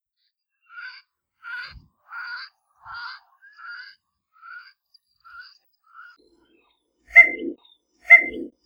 Anas versicolor versicolor - Pato capuchino
patocapuchino.wav